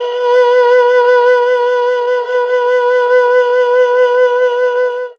52-bi15-erhu-p-b3.wav